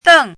chinese-voice - 汉字语音库
deng4.mp3